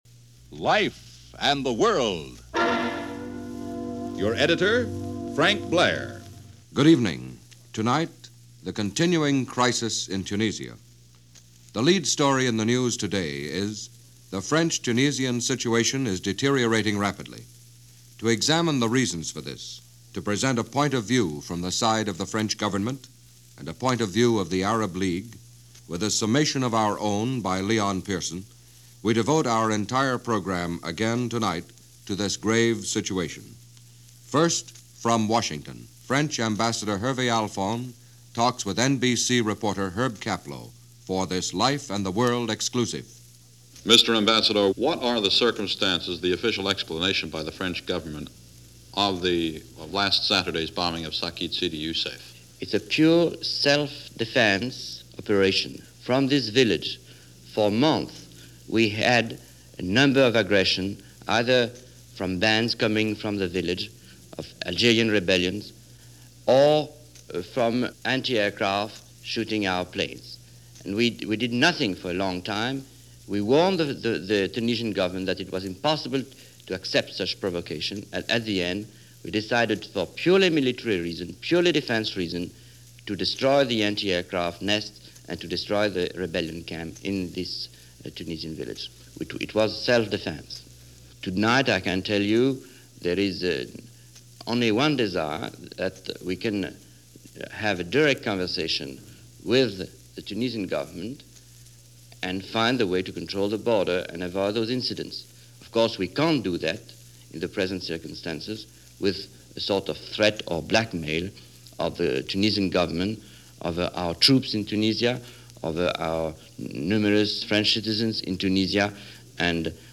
In the middle of that Algerian-Tunisian crisis, NBC Radio and their Life And The World series, devoted two nights to the crisis with interviews and analysis.